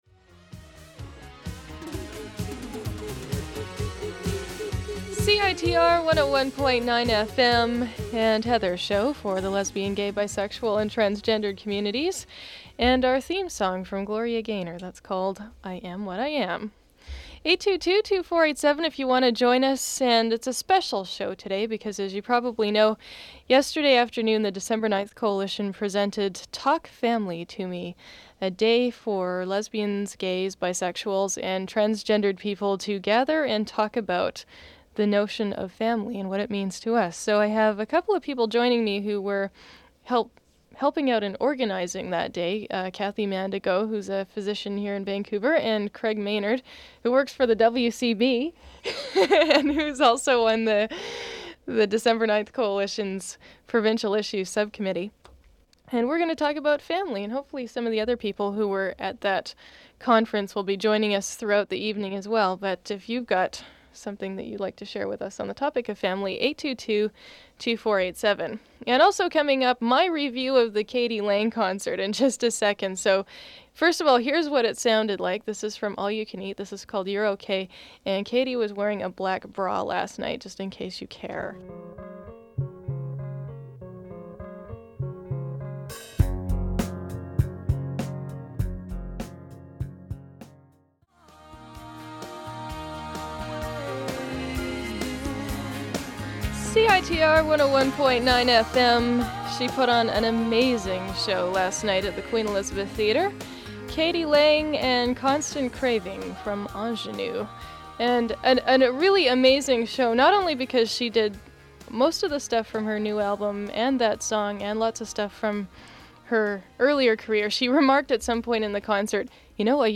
Episode features discussions with family physician